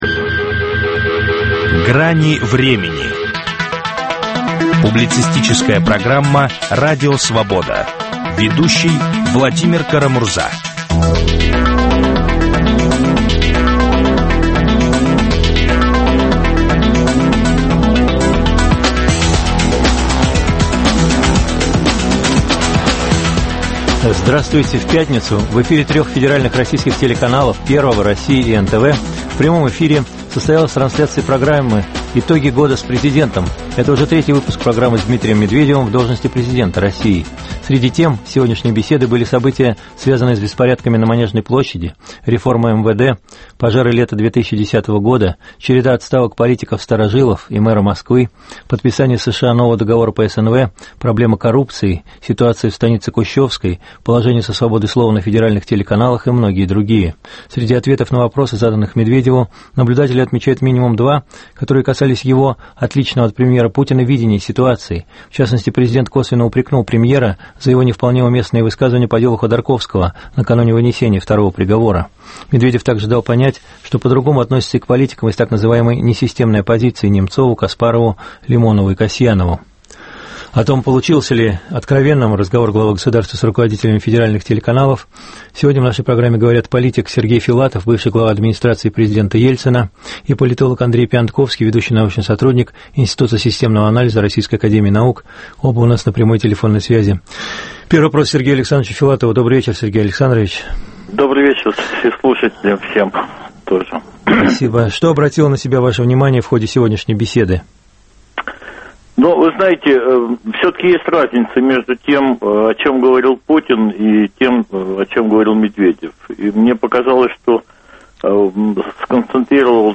Получился ли откровенным разговор главы государства с руководителями федеральных телеканалов? Об этом говорят политик Сергей Филатов и политолог Андрей Пионтковский.